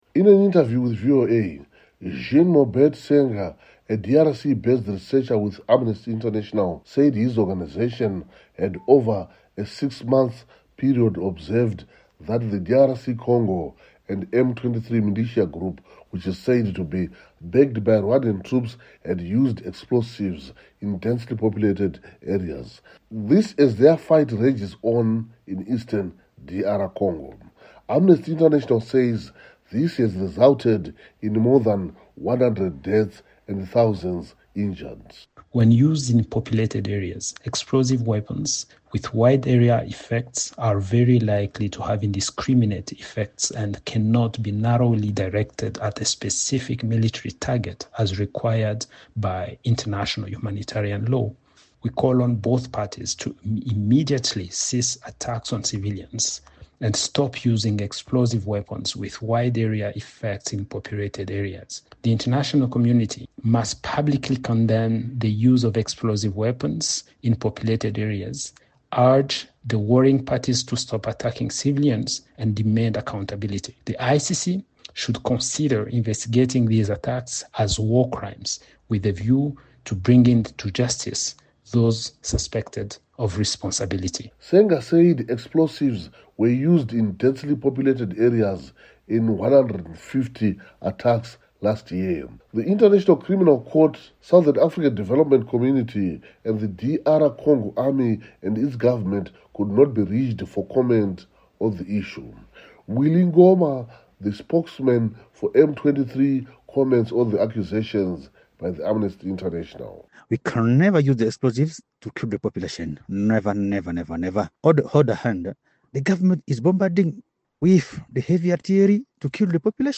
has details from Harare, Zimbabwe